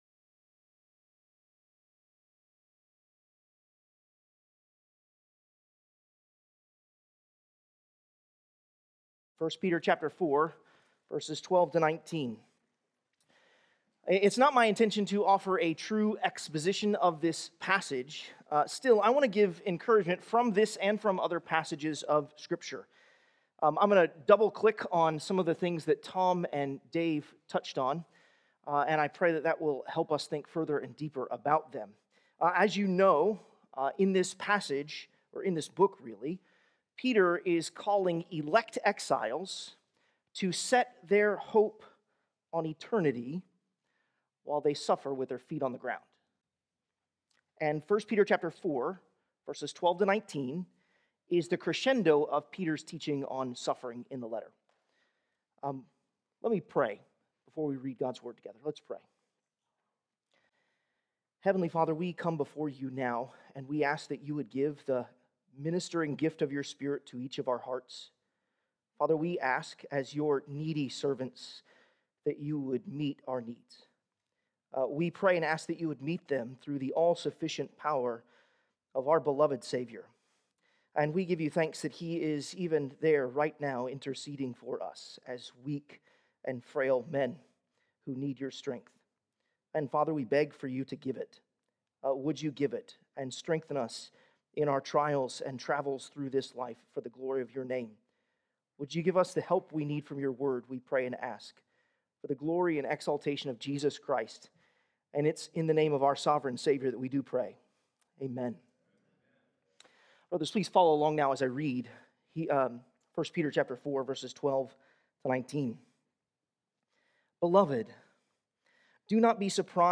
The Necessity of Pastoral Endurance" 2025 Pastors' Seminar in Woodbridge, Virginia.
Sermons